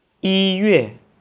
(Click on any Chinese character to hear it pronounced.
yiyue.wav